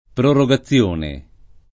prorogazione [ proro g a ZZL1 ne ]